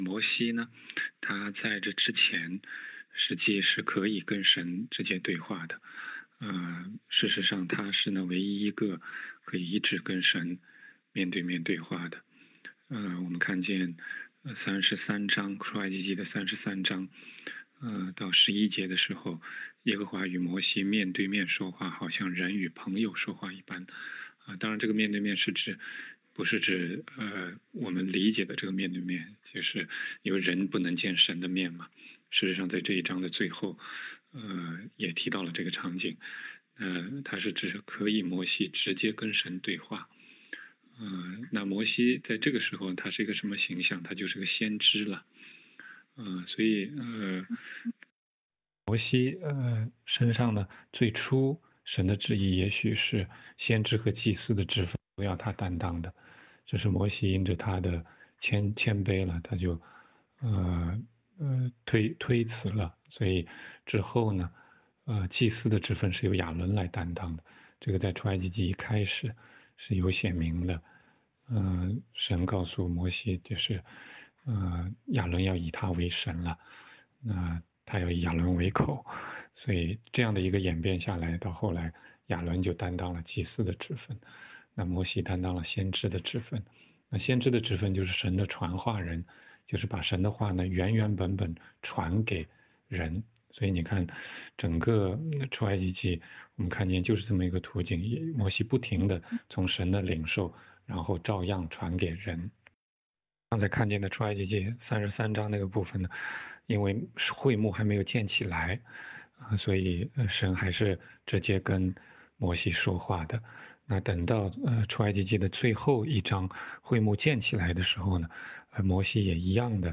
16街讲道录音 - 神开始呼召摩西向以色列人传话，为什么后面立的大祭司却是亚伦？
问题解答